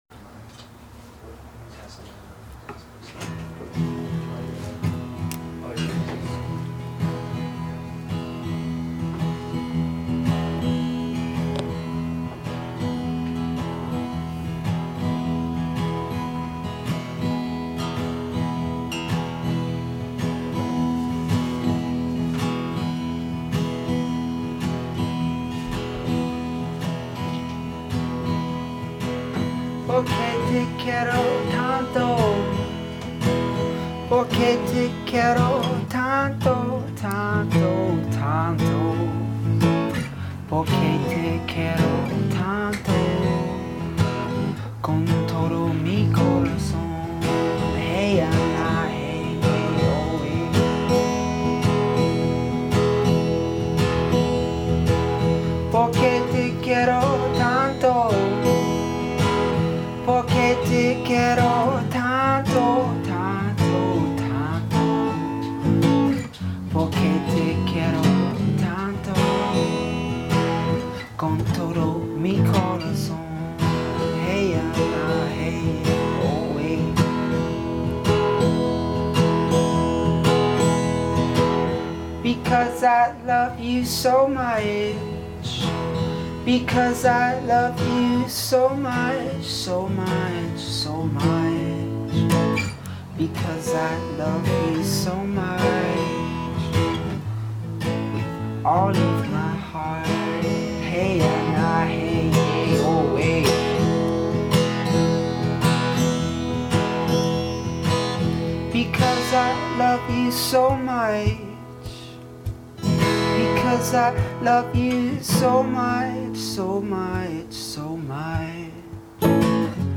His music too is noticeably more concentrated and restrained